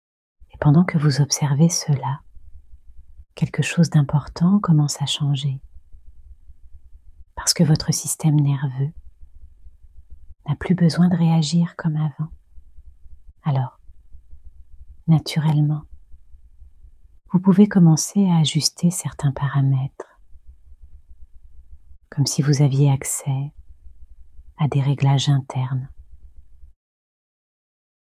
Programme d’hypnose en 3 séances